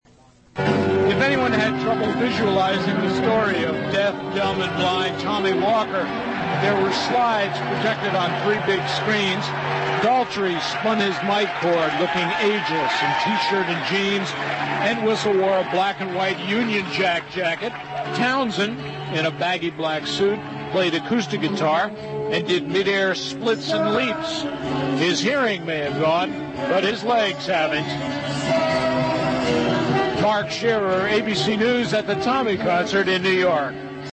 So, in 1989, the Who reunited – not for the first time, nor for the last – to perform Tommy at Madison Square Garden, and here’s how I described it for ABC Radio News 30 years ago: